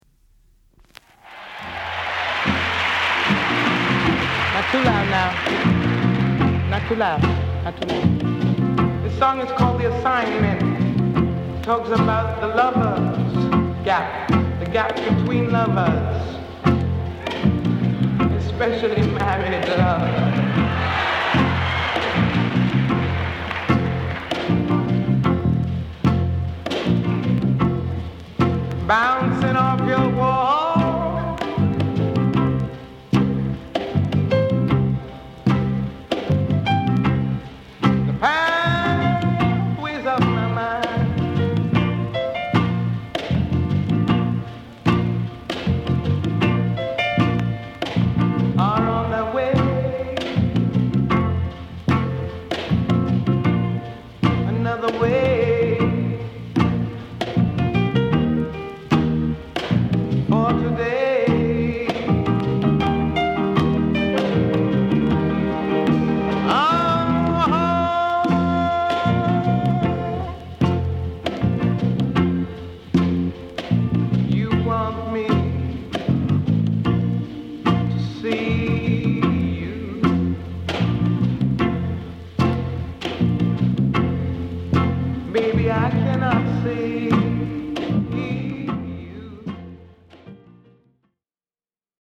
USオリジナル プロモ(NOT FOR SALE) STEREO 7インチ 45RPM.
A面 : 軽微なノイズを多少感じるとこはありましたが大きな問題はなく大半は概ね良好に聞けます。